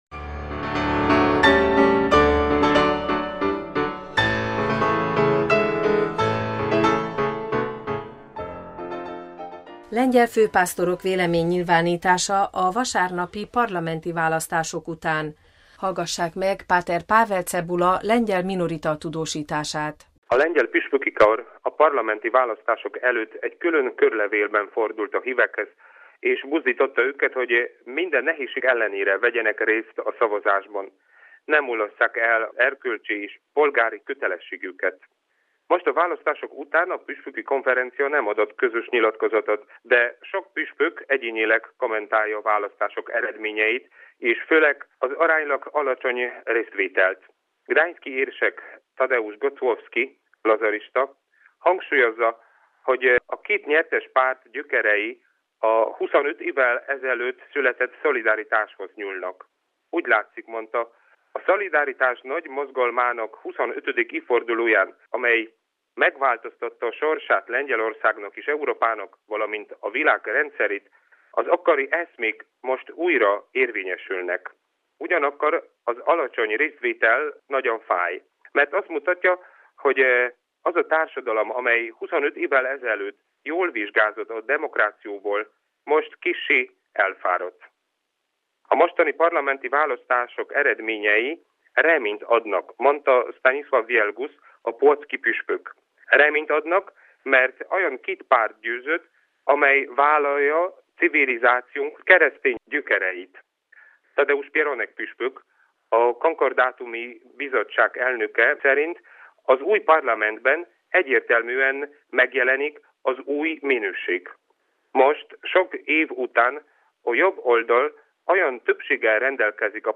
tudósítása